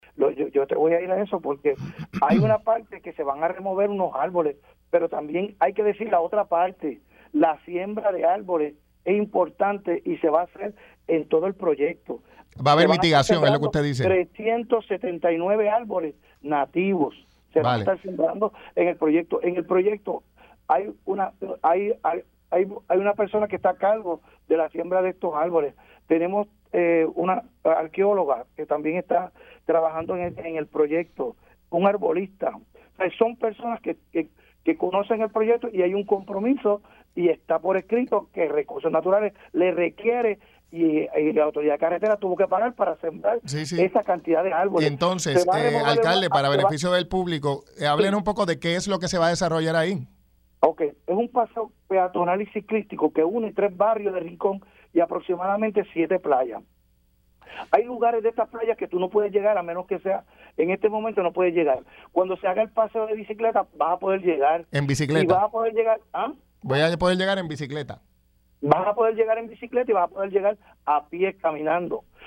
Yo entiendo que la protesta no está justificada porque este proyecto cuenta con todos los endosos y permisos del Gobierno de Puerto Rico y el Gobierno federal. Y como te digo, esto es un proyecto que es público y es para la gente. Y tú escuchas a la gente hablando de que la playa es del pueblo, pero la playa es de los surfers, la playa es de todos los individuos, la playa es de los ancianitos que puedan bajar en sillón de ruedas. Por este proyecto van a poder bajar sin problema y disfrutar de una playa que las personas con impedimentos ahora no pueden“, aseguró en entrevista para Pega’os en la Mañana.